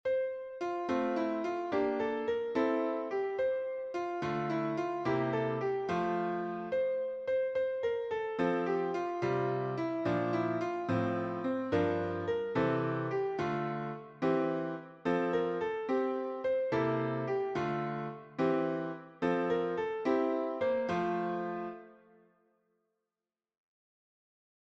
French carol